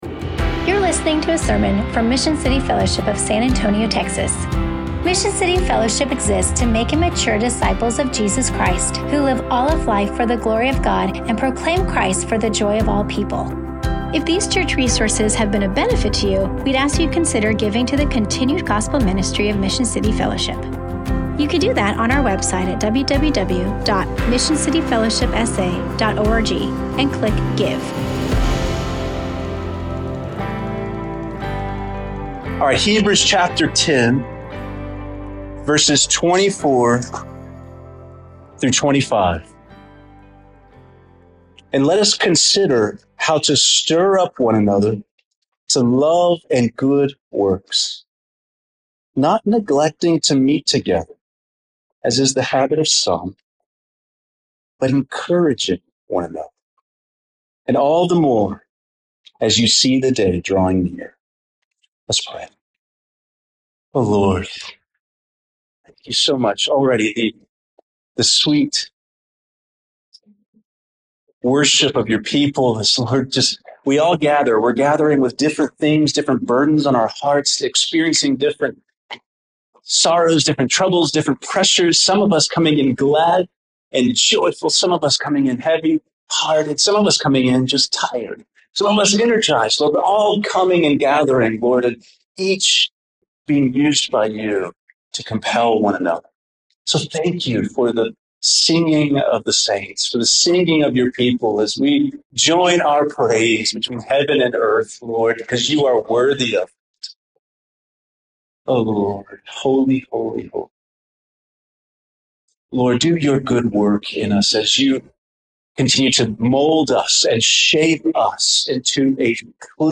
A short portion of the sermon audio – up until the 9:55 mark – may be a bit unclear at times.